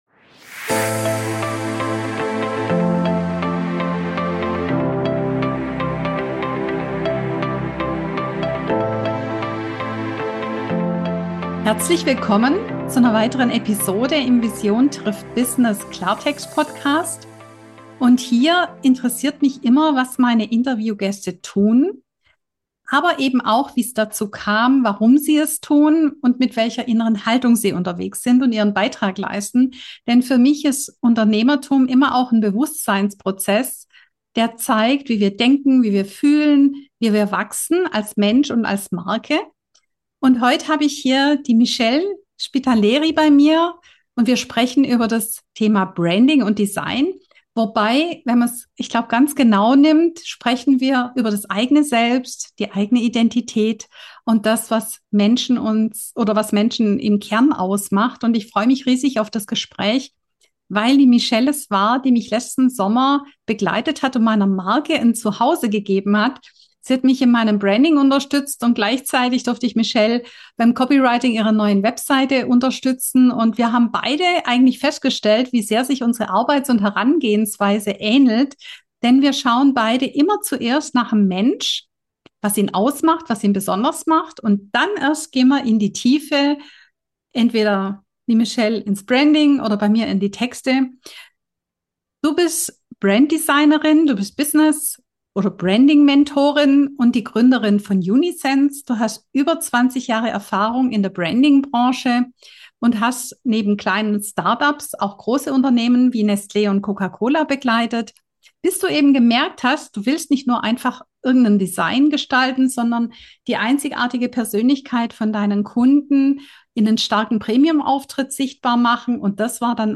Eine Besonderheit dieser Folge: Das Gespräch wird teilweise auf Schweizerdeutsch geführt.